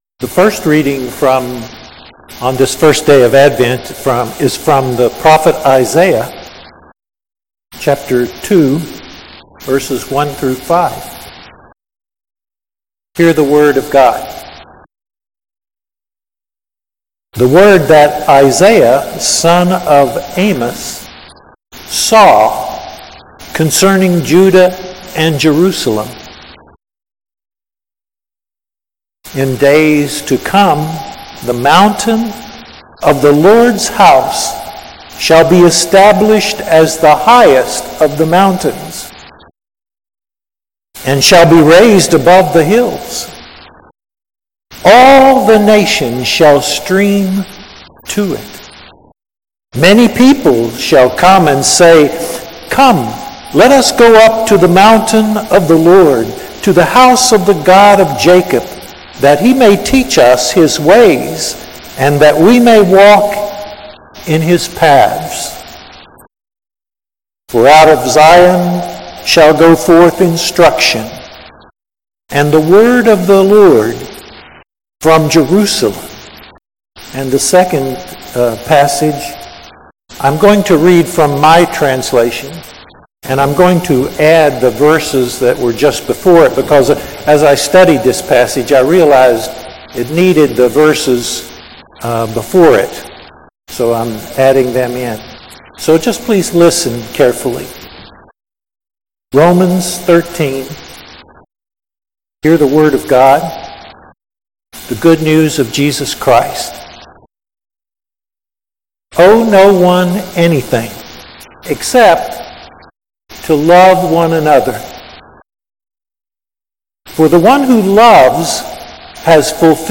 Sermon: The Armor Of Light | First Baptist Church, Malden, Massachusetts
Sunday Worship Service with Communion December 1